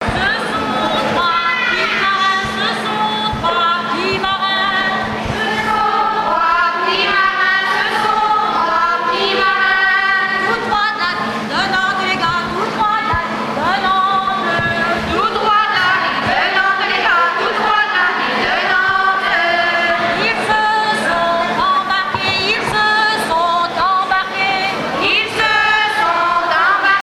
enregistrée à l'usine de conserves de sardines Amieux
danse : ronde : rond de l'Île d'Yeu
Chansons traditionnelles
Pièce musicale inédite